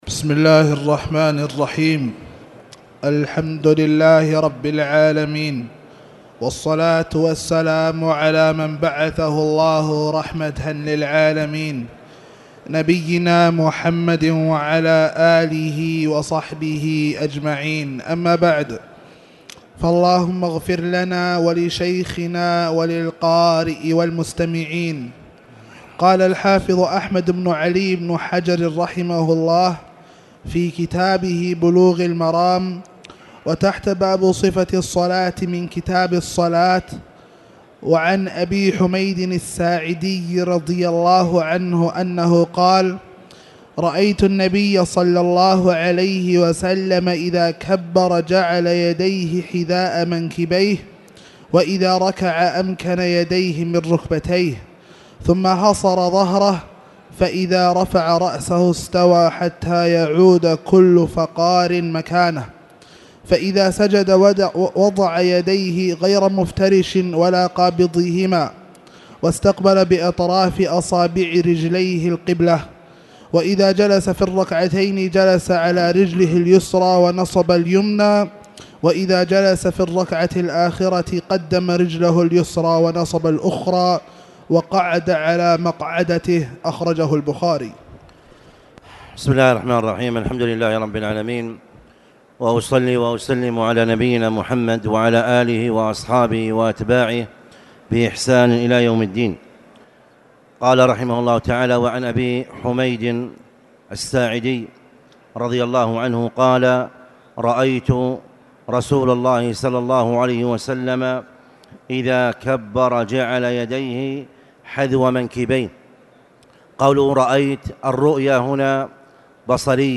تاريخ النشر ٦ رجب ١٤٣٨ هـ المكان: المسجد الحرام الشيخ